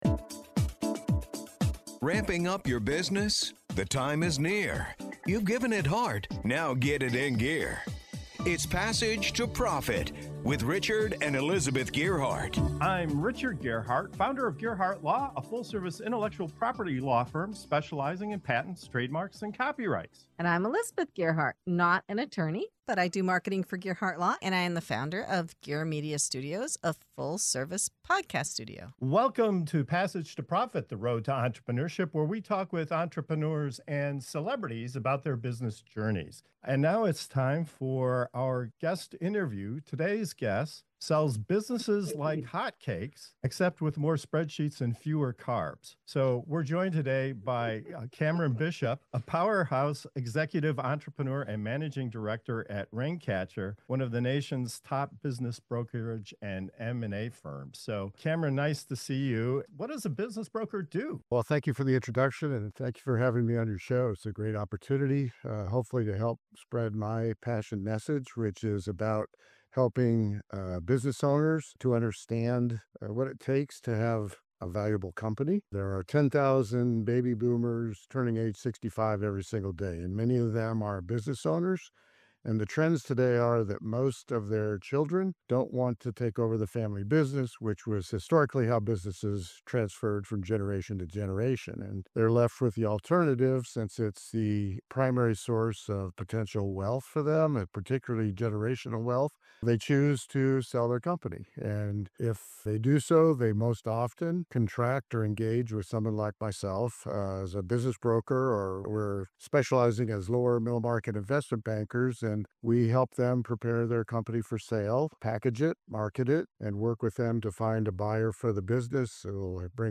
Passage to Profit Show interview